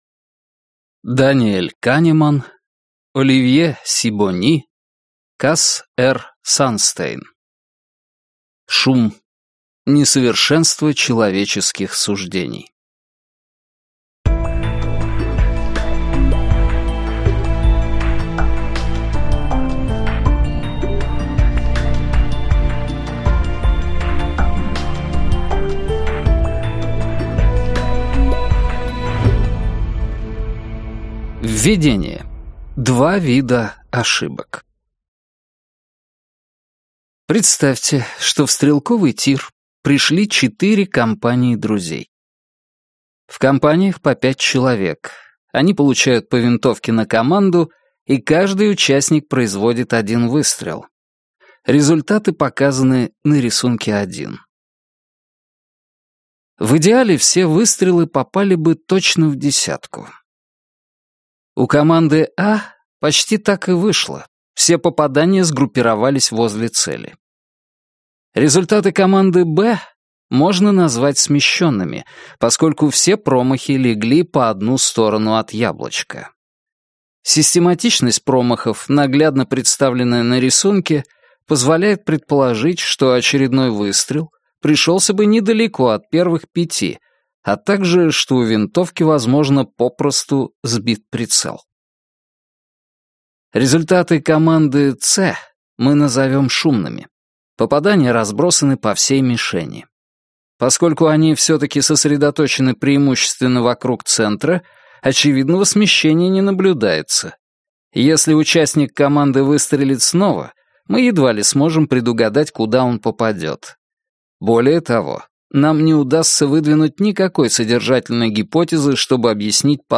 Аудиокнига Шум. Несовершенство человеческих суждений | Библиотека аудиокниг